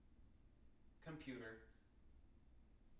wake-word
tng-computer-75.wav